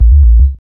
1808R BASS.wav